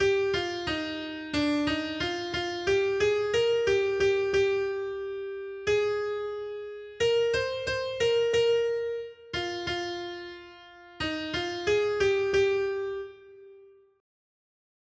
Noty Štítky, zpěvníky ol134.pdf responsoriální žalm Žaltář (Olejník) 134 Skrýt akordy R: Blaze všem, kdo se uchylují k Hospodinu. 1.